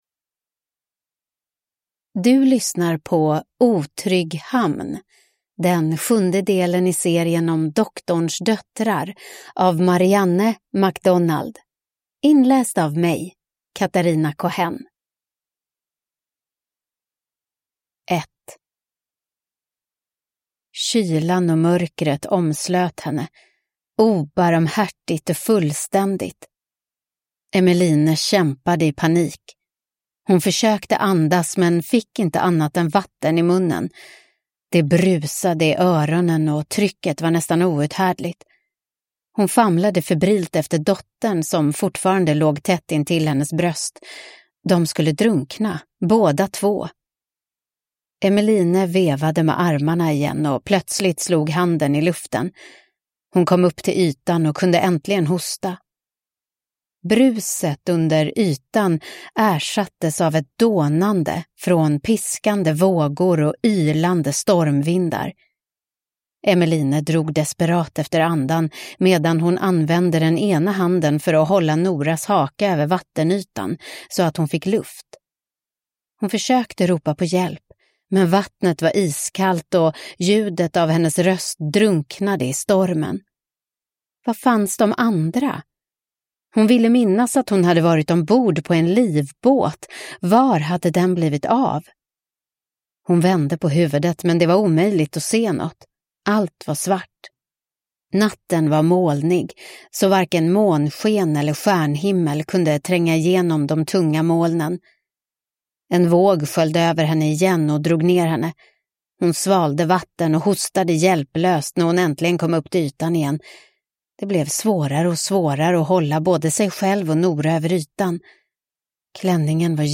Otrygg hamn (ljudbok) av Marianne MacDonald